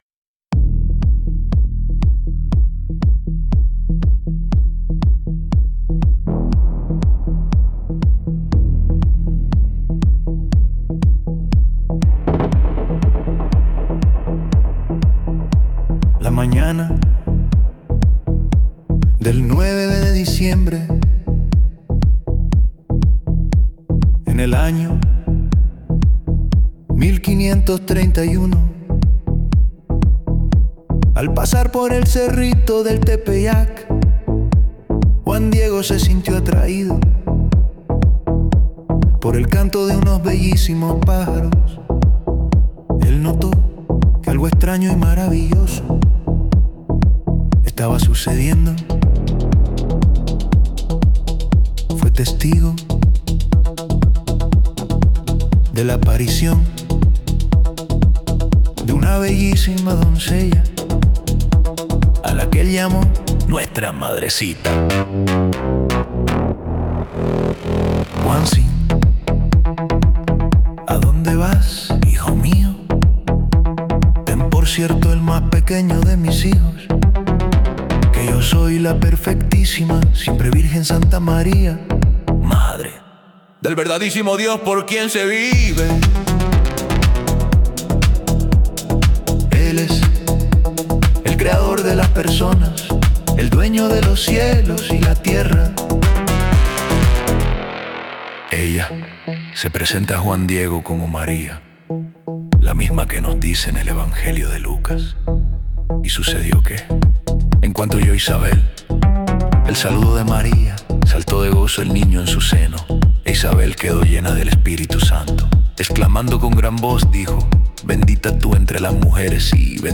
Escucha Música Muestra 5: electrónico profundo